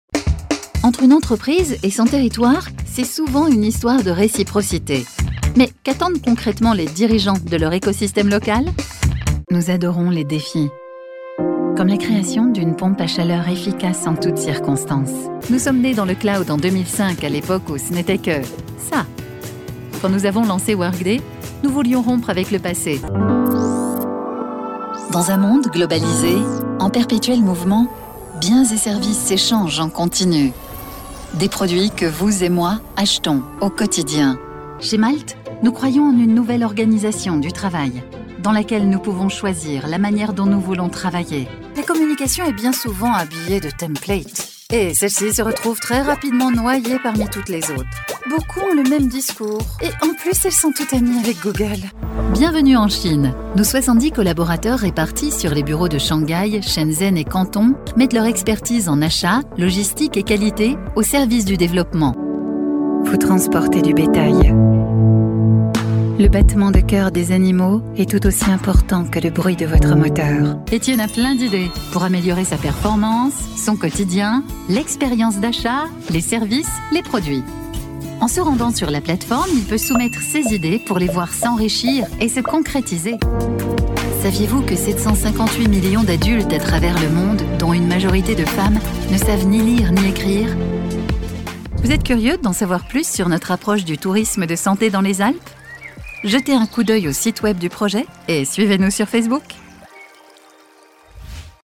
Female
Assured, Authoritative, Bright, Bubbly, Character, Cheeky, Children, Confident, Cool, Corporate, Deep, Engaging, Friendly, Gravitas, Natural, Posh, Reassuring, Sarcastic, Smooth, Soft, Streetwise, Wacky, Warm, Witty, Versatile, Young
dry studio read.mp3
Microphone: Neumann U87 + TLM103
Audio equipment: Apollo twin + RME fire Face ; Manley Vox Box, Booth acoustically treated